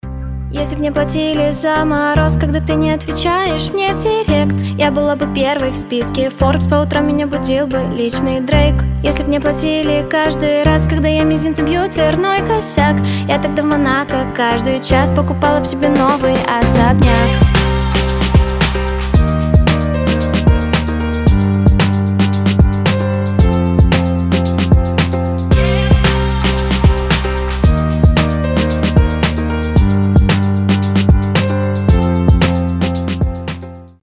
• Качество: 320, Stereo
поп
забавные
красивый женский голос
пародии